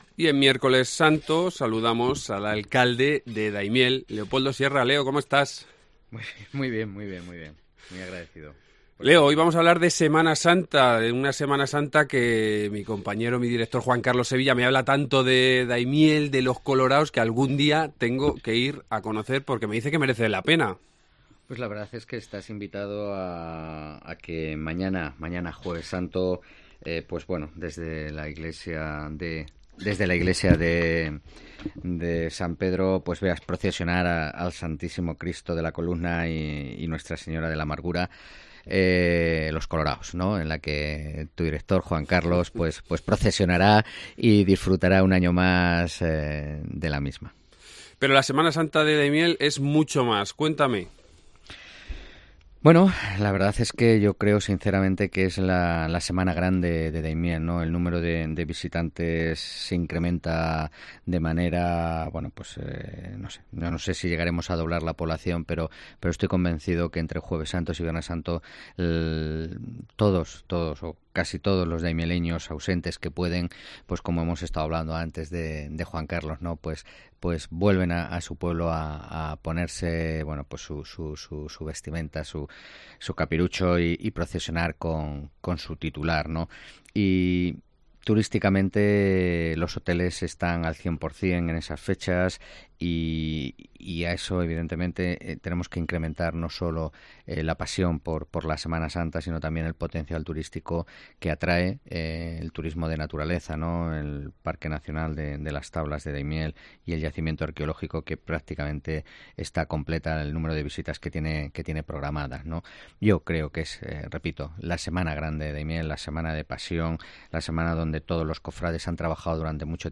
Miércoles Santo, hoy nos vamos hasta Daimiel, a una Semana Santa que les recomiendo encarecidamente. Leopoldo Sierra es su alcalde y con él charlamos de la semana grande de una localidad que además nos ofrece dos enclaves únicos, Las Tablas de Daimiel y la Motilla del Azuer.